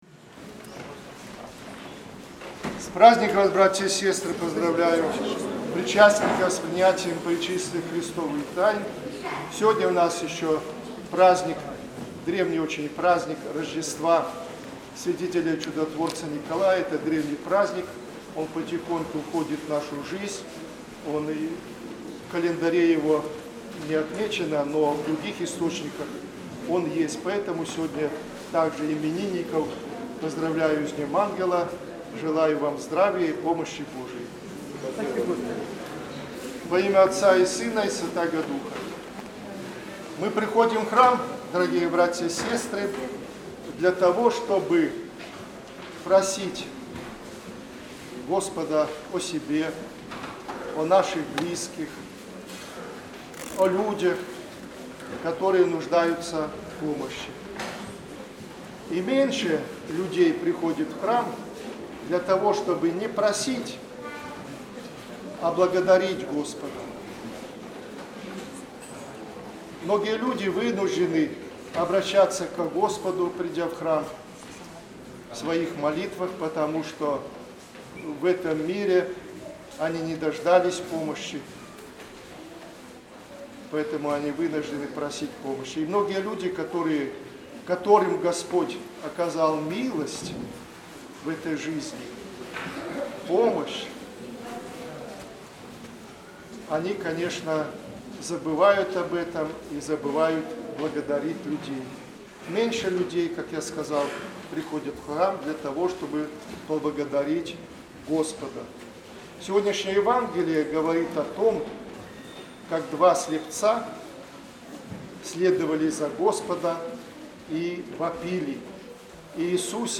Проповедь прот.